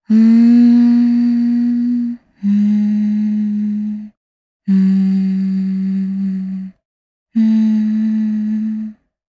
Xerah-Vox_Hum_jp_b.wav